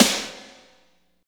53.01 SNR.wav